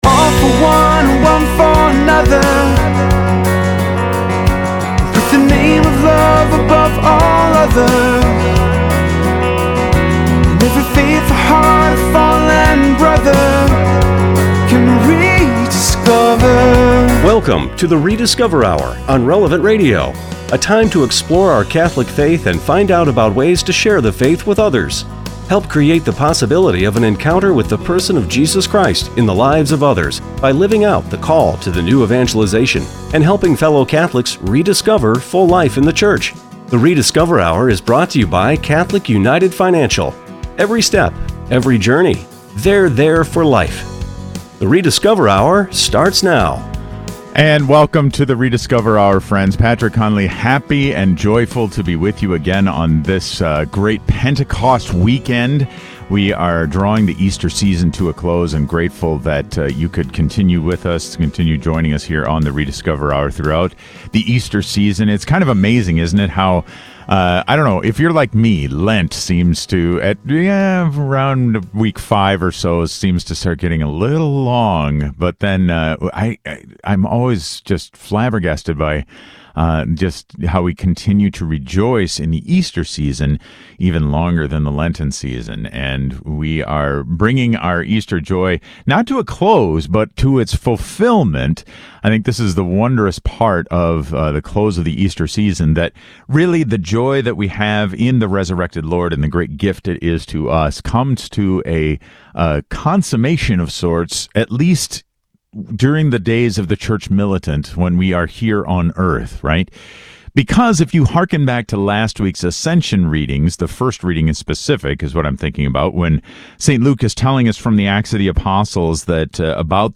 On this special, extended-interview edition of the Rediscover: Hour, Archbishop Bernard Hebda joins us for a 30-minute discussion about the Bishops’ announcement, as well as updated information on the new executive order to re-open parishes.